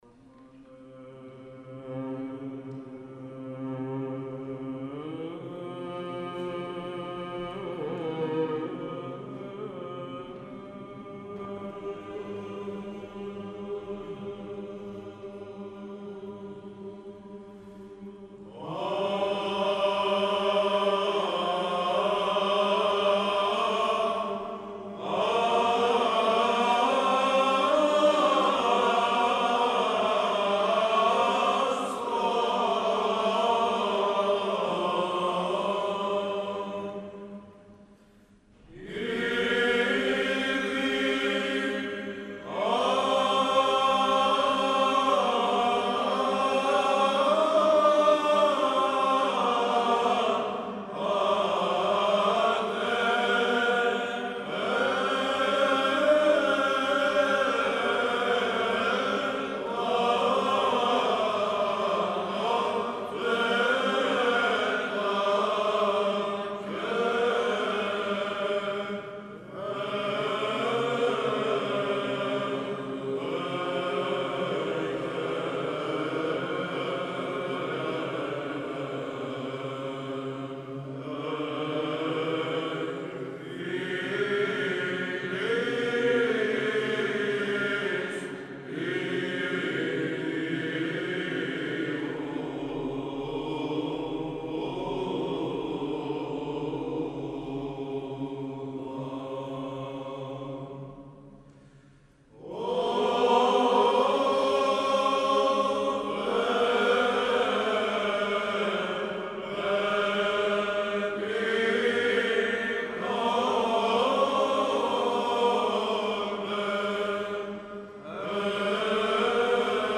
Δέος, συγκίνηση και πανδαισία ήταν τα συναισθήματα που κυριαρχούσαν την Τετάρτη 20 Δεκεμβρίου το απόγευμα, στον Ιερό Μητροπολιτικό Ναό Ευαγγελισμού της Θεοτόκου Λαμίας και τα ένιωσαν οι εκατοντάδες παρευρισκόμενοι στην Χριστουγεννιάτικη εκδήλωση που πραγματοποίησε η Ιεράς Μητρόπολις μας, προγευόμενοι τη χαρά της Ενανθρωπήσεως του Υιού και Λόγου του Θεού.
Χριστουγεννιάτικους ύμνους